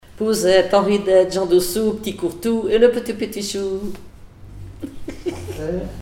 formulette enfantine : jeu des doigts
Comptines et formulettes enfantines